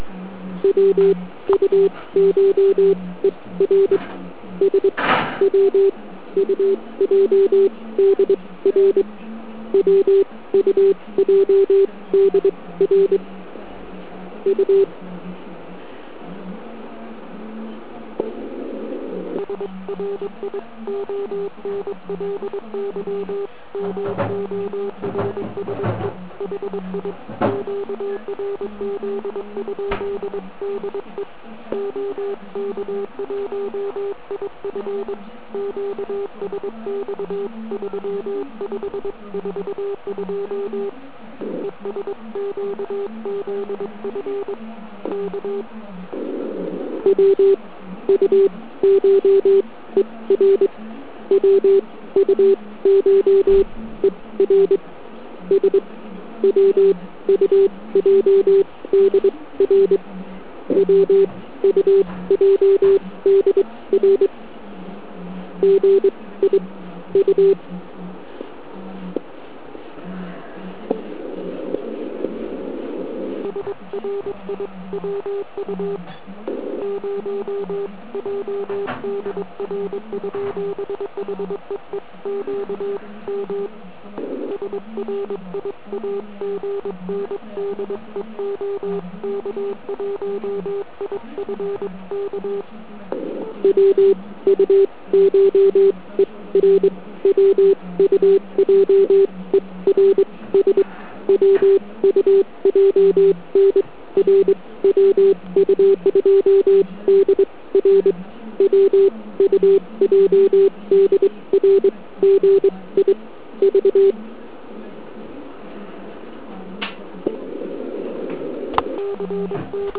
Prokazatelně mne někdo volal z OK. Opravdu se to nedalo přečíst.
Ne morse volání